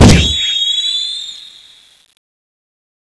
flyzombie_downhill_start.wav